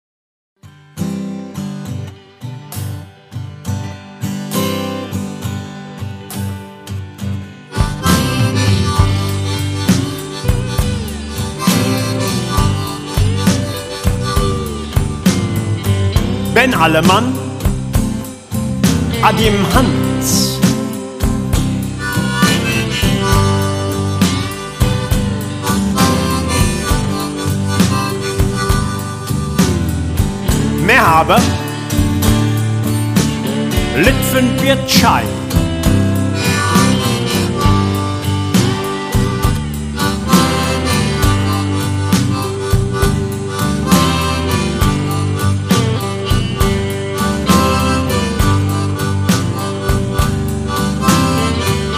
Authentische Lieder eines Abenteurers und Straßenmusikers.